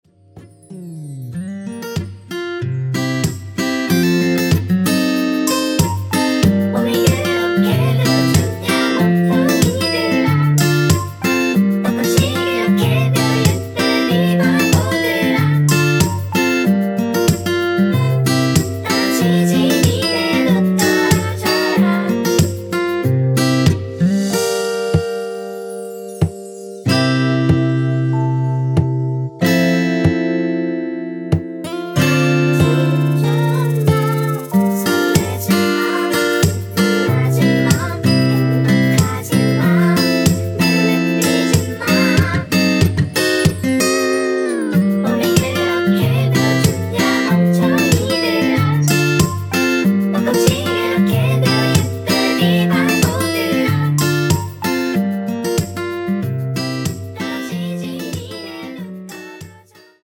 원키에서(+4)올린 코러스 포함된 MR입니다.
앞부분30초, 뒷부분30초씩 편집해서 올려 드리고 있습니다.